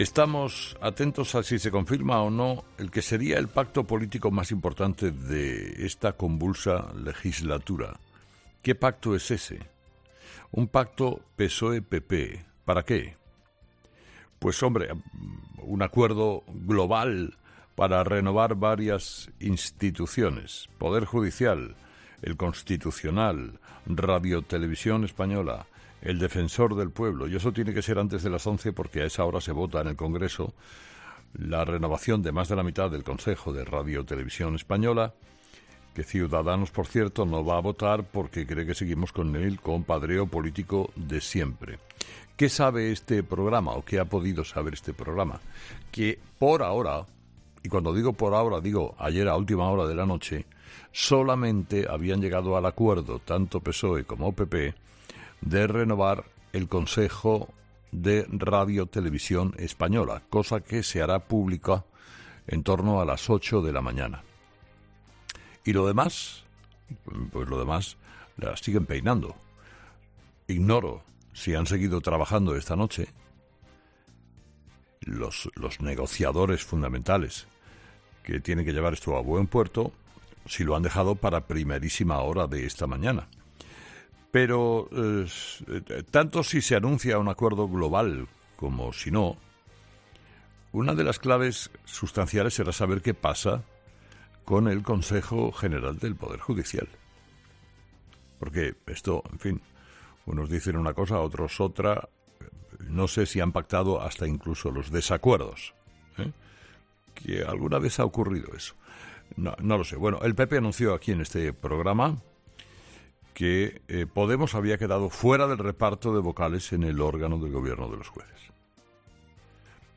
Carlos Herrera, director y presentador de 'Herrera en COPE', ha comenzado el programa de este jueves analizando las principales claves de la jornada, que pasa por los diferentes aspectos relacionados con la actualidad política de nuestro país y los datos que la pandemia sigue arrojando a nuestra sociedad, a poco menos de un mes de cumplir un año de la entrada en vigor del estado de alarma decretado por Pedro Sánchez.